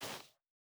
Bare Step Snow Medium E.wav